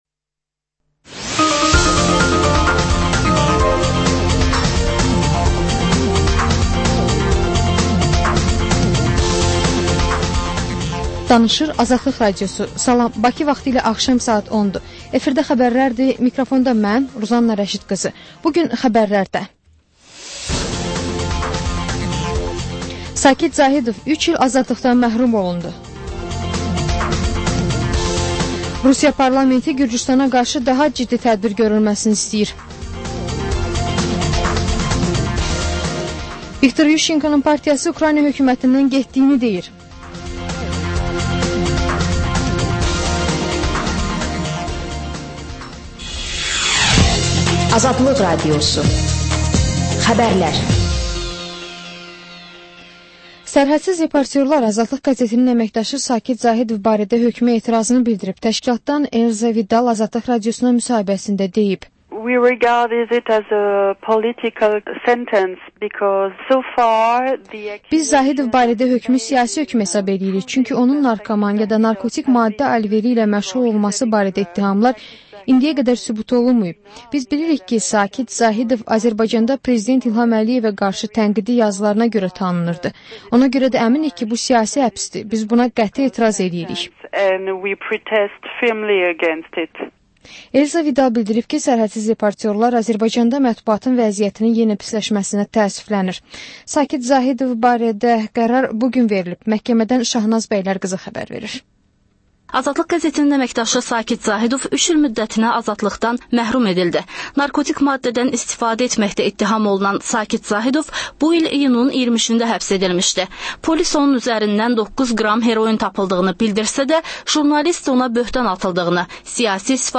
Xəbər, reportaj, müsahibə. Sonra: 14-24: Gənclərlə bağlı xüsusi veriliş.